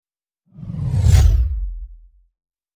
Звуки пуф